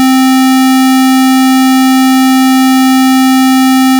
Band Limited Square Wave
Here’s how a band limited square wave looks and sounds compared to a non band limited square wave, like the ones we created in the last chapter.
square.wav